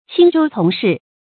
青州從事 注音： ㄑㄧㄥ ㄓㄡ ㄘㄨㄙˊ ㄕㄧˋ 讀音讀法： 意思解釋： 青州：古代州名，在今山東東部；從事：古代官名。